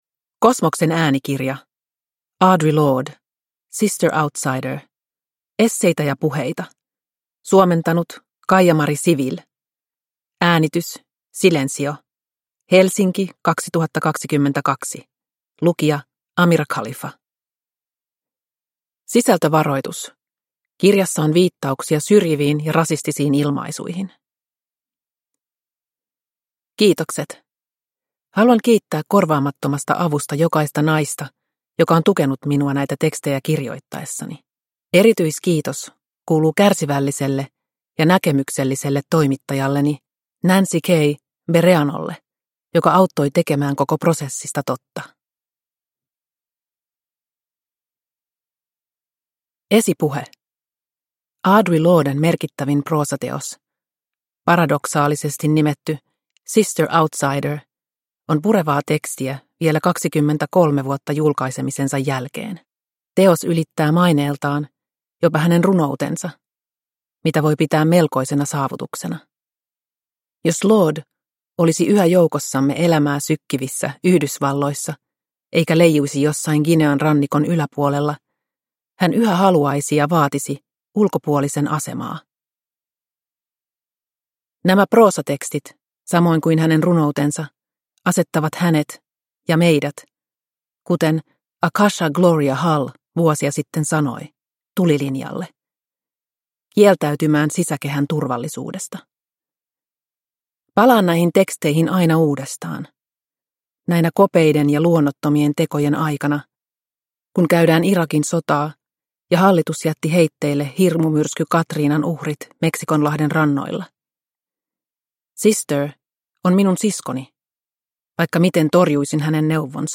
Sister Outsider – Ljudbok – Laddas ner